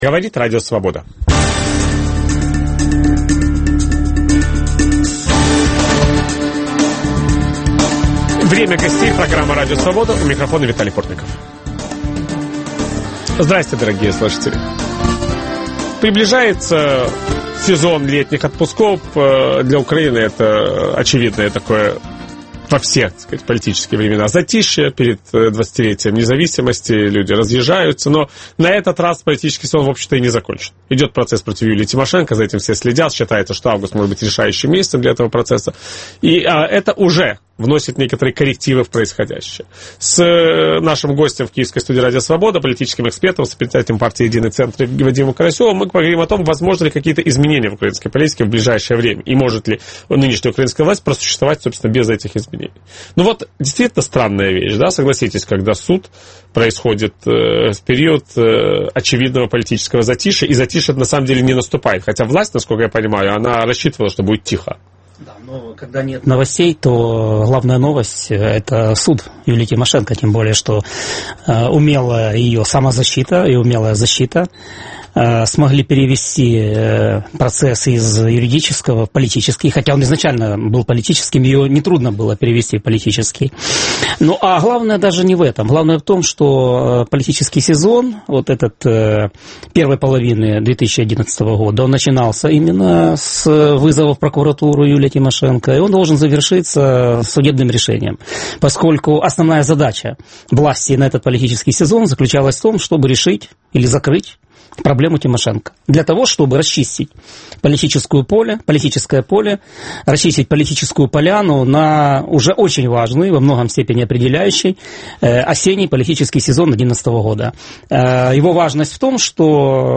Возможны ли коррективы в политике украинской власти? В программе участвует политолог